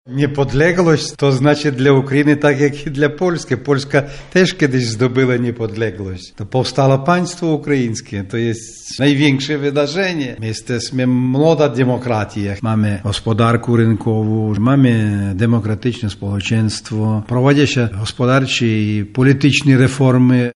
O tym czym dla nich jest niepodległość mówi konsul generalny Ukrainy w Lublinie, Iwan Hrycak.